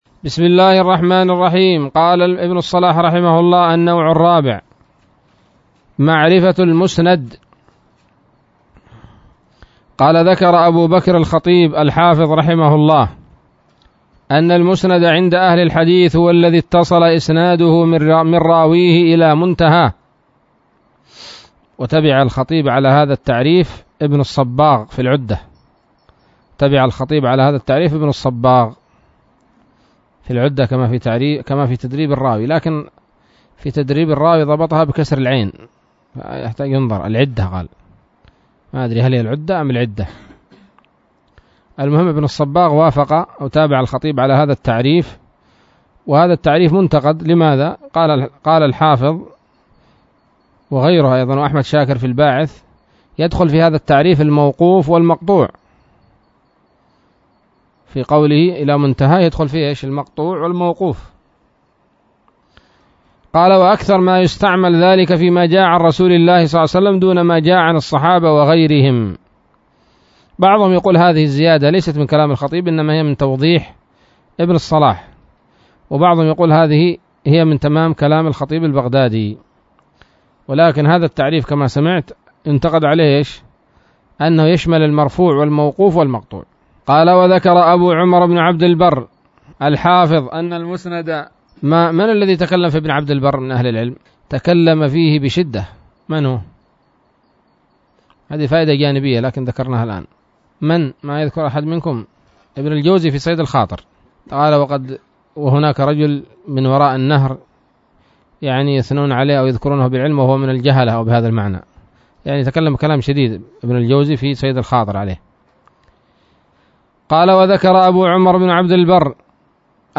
الدرس التاسع عشر من مقدمة ابن الصلاح رحمه الله تعالى